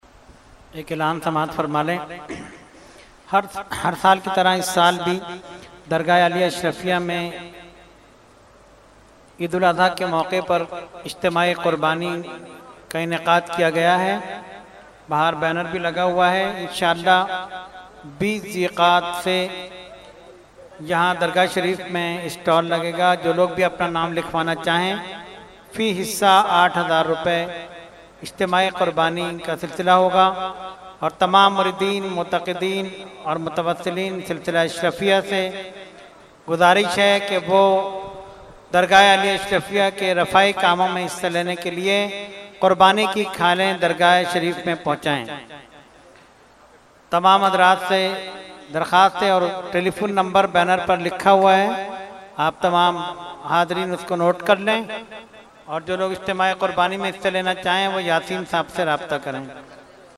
Category : Announcement | Language : UrduEvent : Urs Ashraful Mashaikh 2017